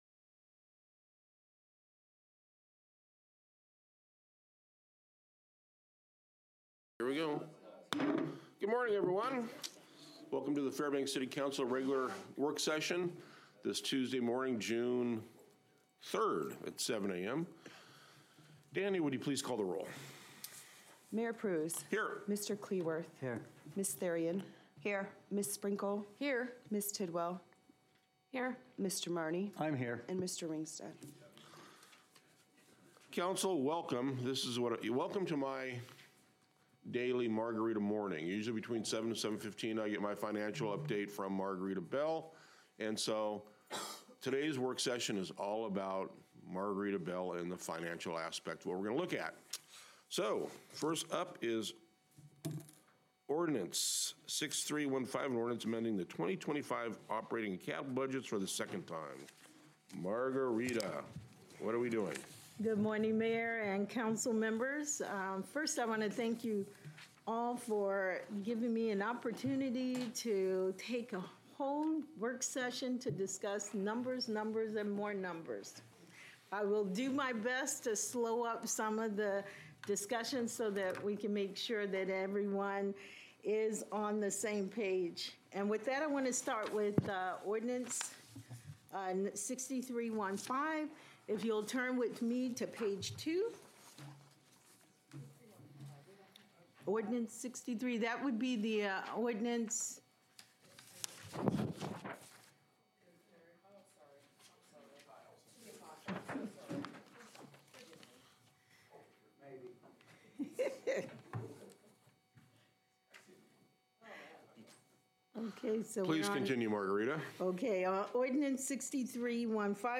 Regular City Council Work Session